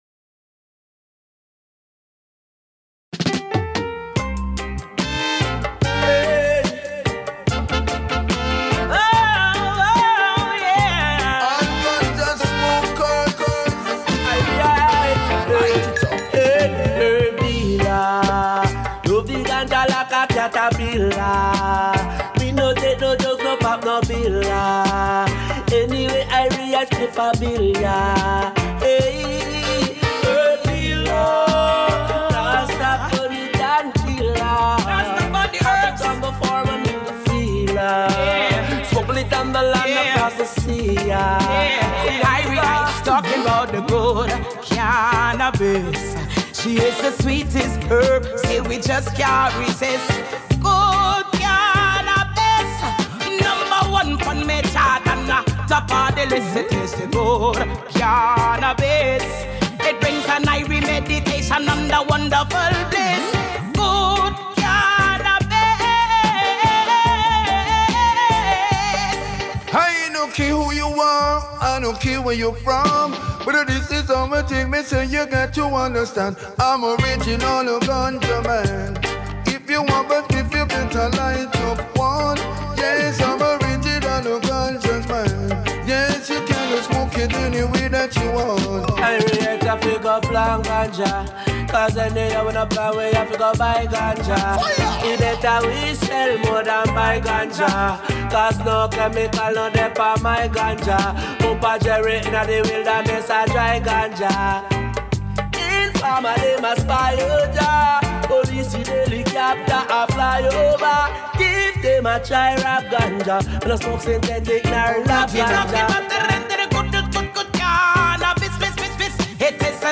The Best Web Radio Show Of Roots Reggae Dub Music